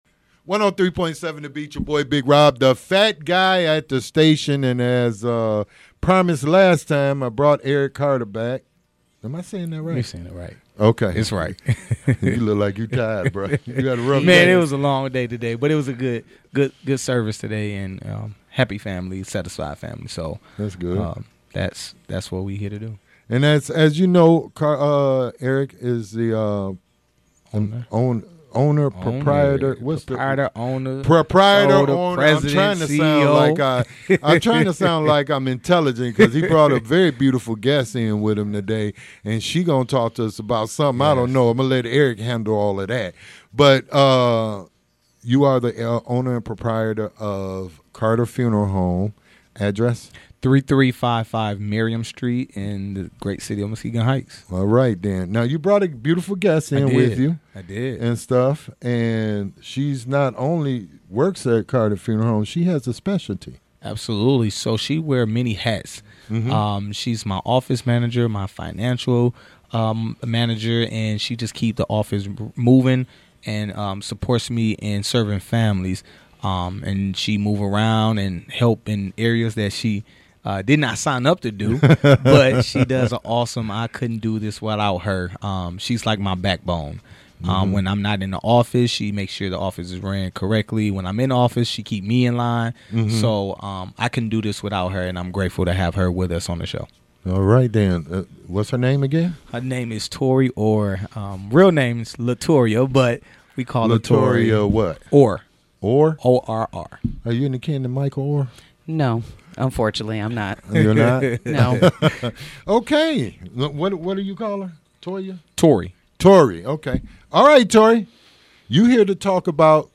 Interview with Carter Funeral Home